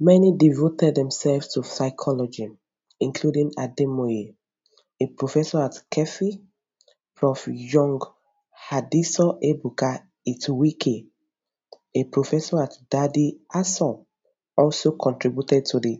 reference_accent.wav